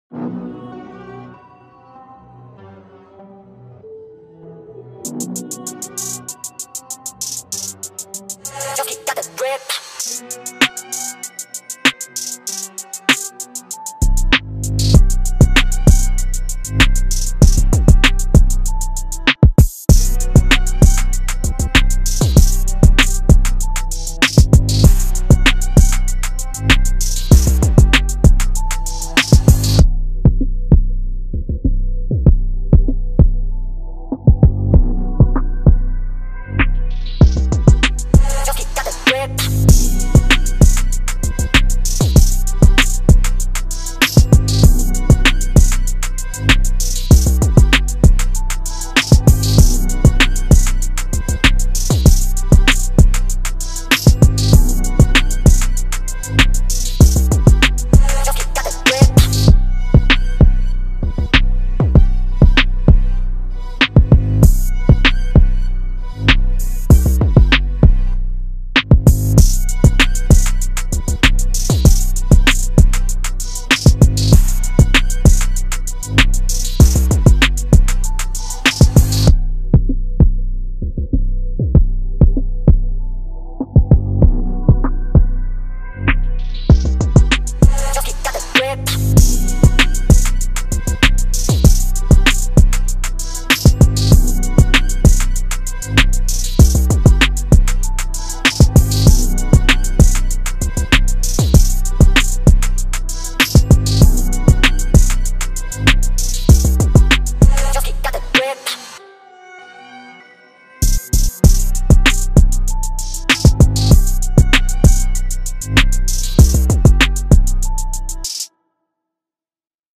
Hier wurde nur der Beat eingereicht.
Keine Runde kein Punkt, aber der Beat ist killer.